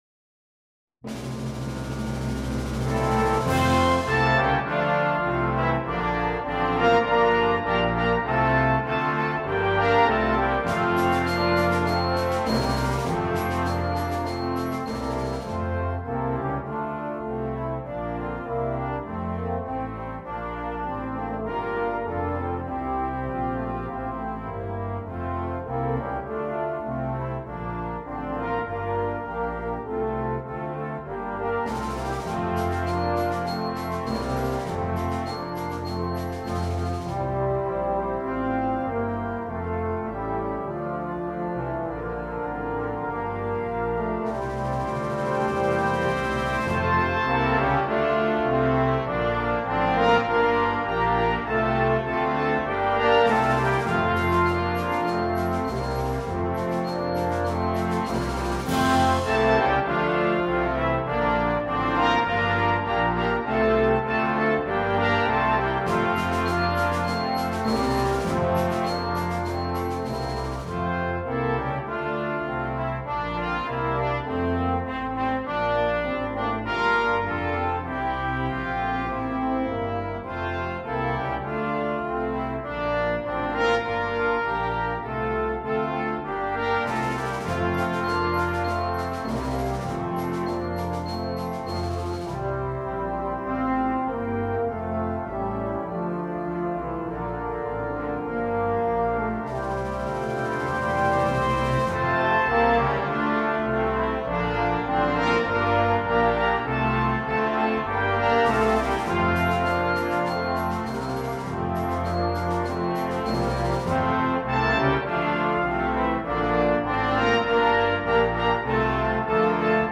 Banda completa
Música de Navidad
fácil